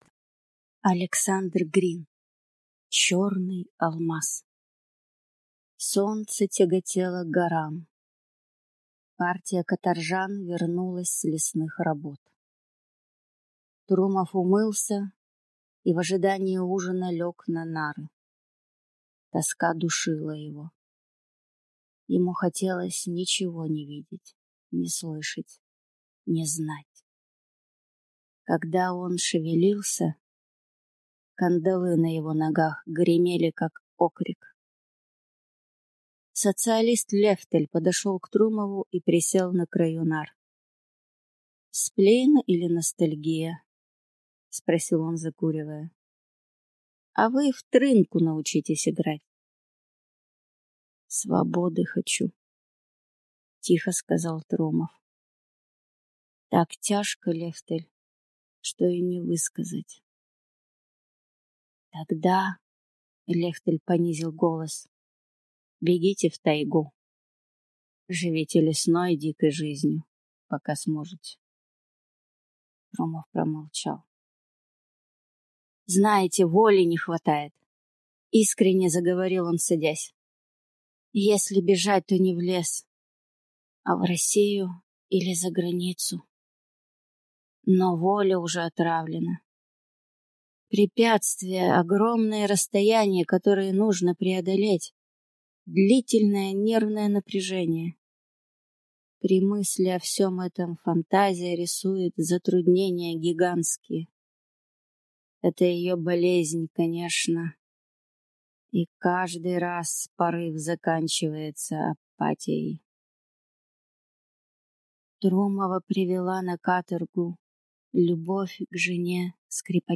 Аудиокнига Черный алмаз | Библиотека аудиокниг